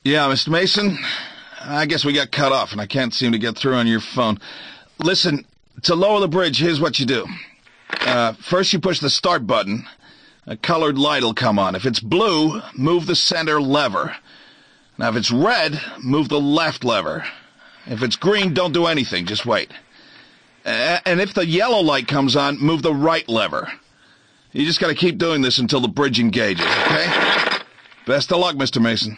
Type: Voicemail